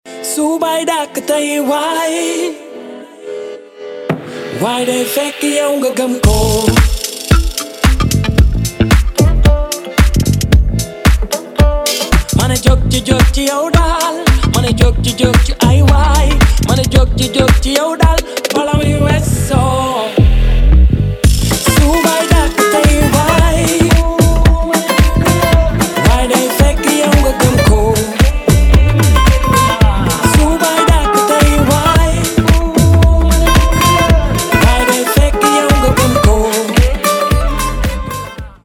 • Качество: 320, Stereo
гитара
заводные
dance
nu disco
озорные